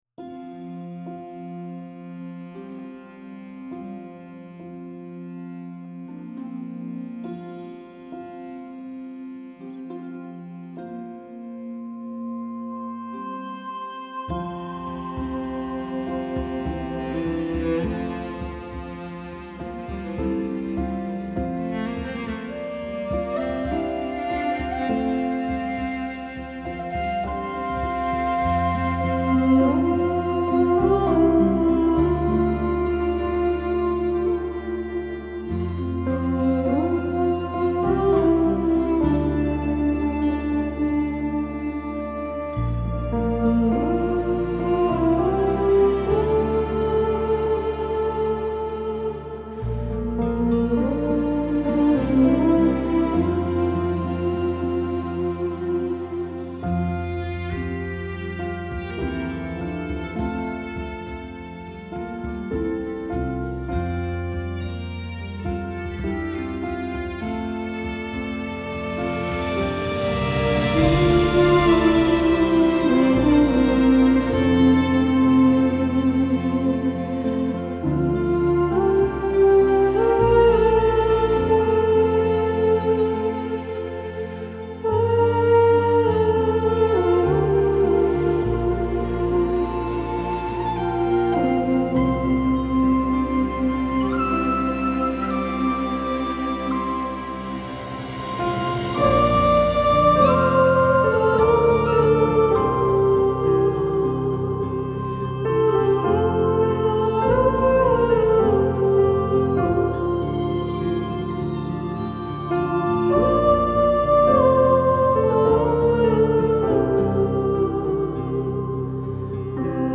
آرامش بخش
موسیقی بی کلام آوایی موسیقی بی کلام رویایی